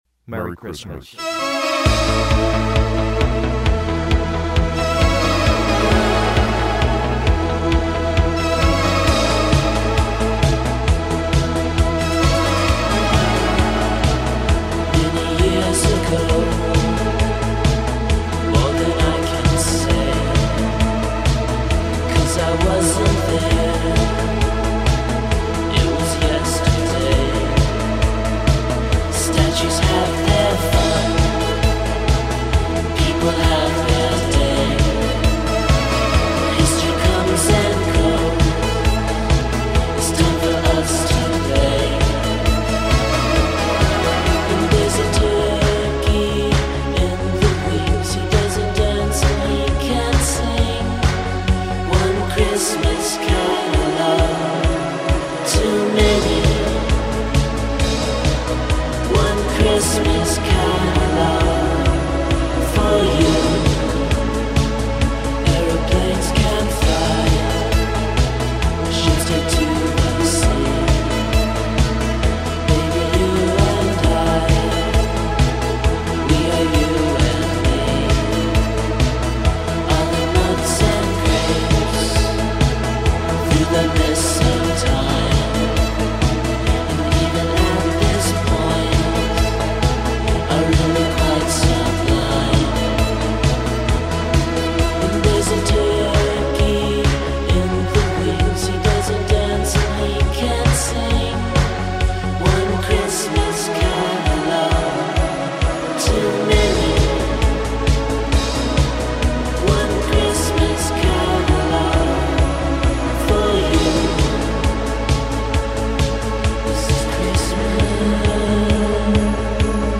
holiday track
cover